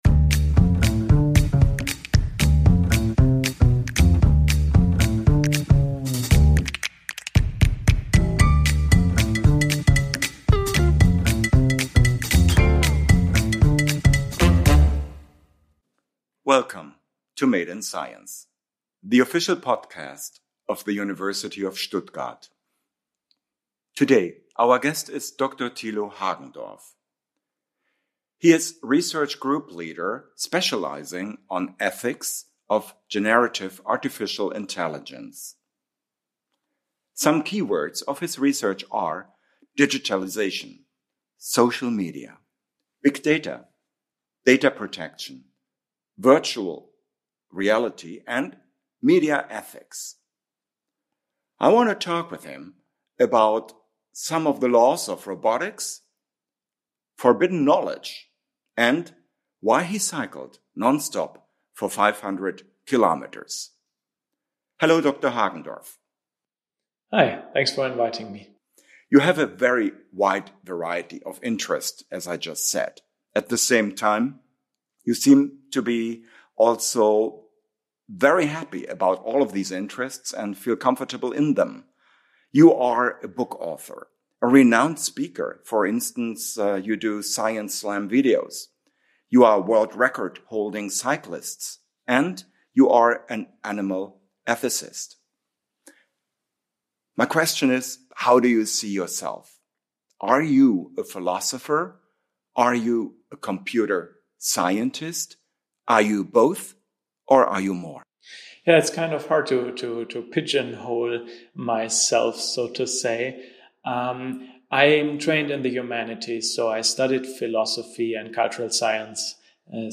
Join us for an inspiring conversation